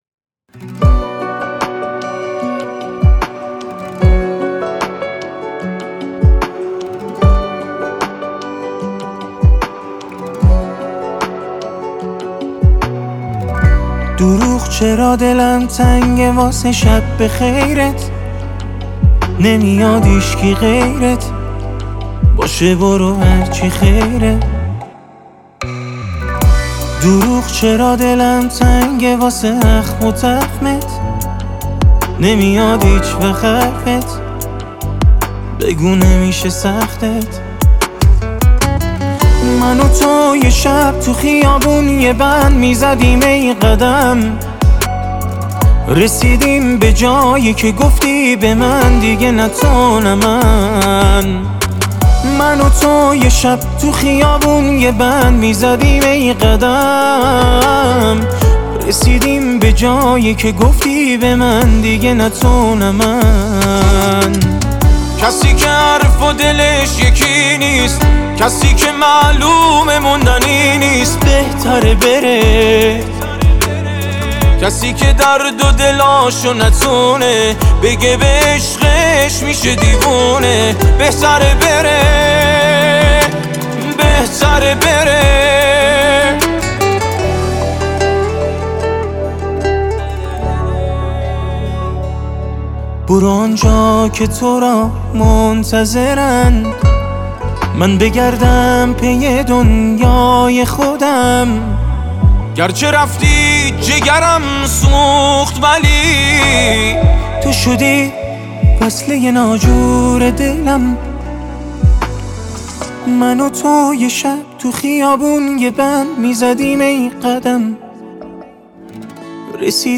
با صدای مرد